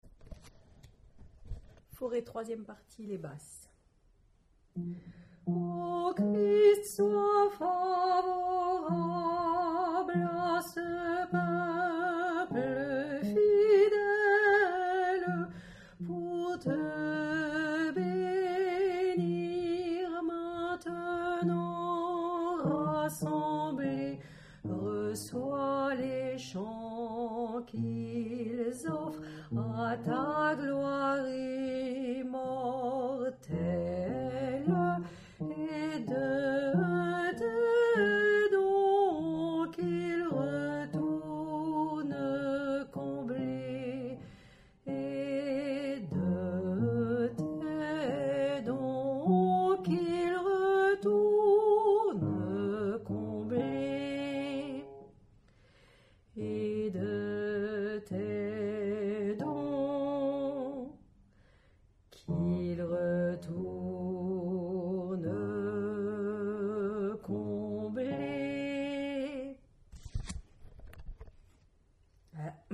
faure3_Basse.mp3